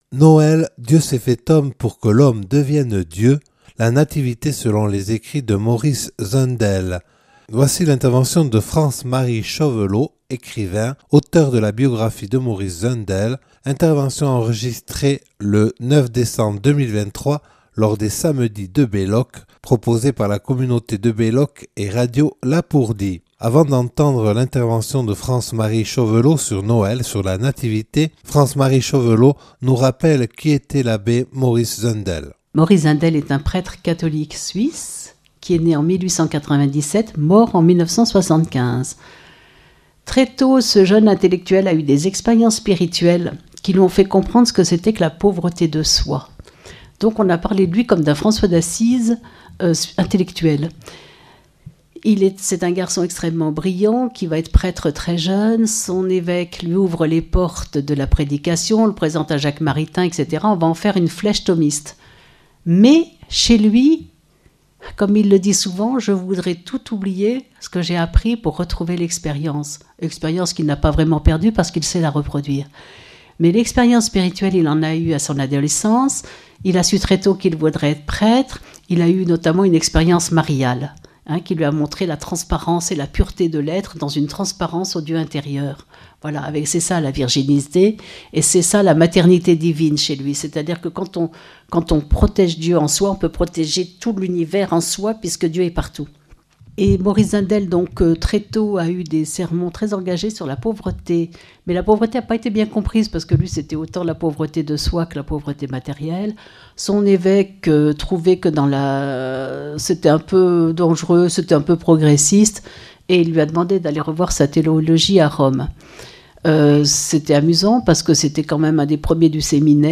(Enregistrée le 9 décembre 2023 lors des Samedis de Belloc).